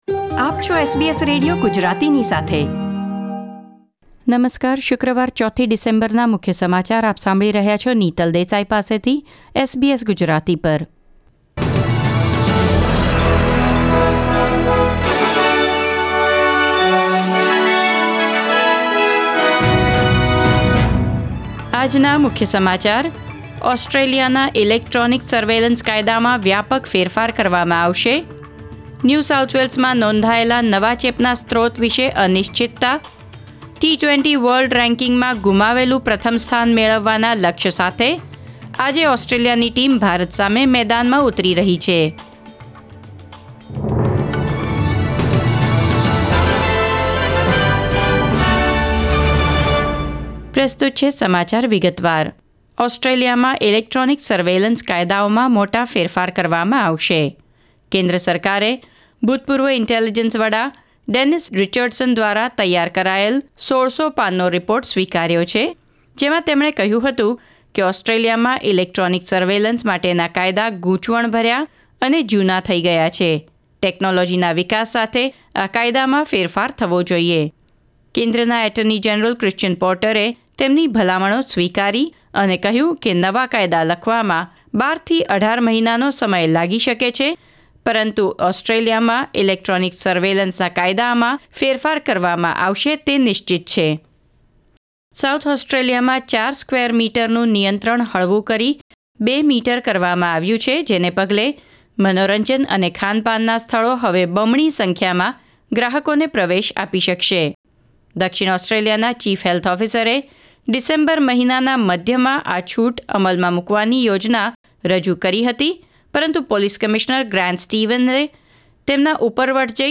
SBS Gujarati News Bulletin 4 December 2020